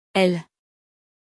• Prononciation : [ɛl]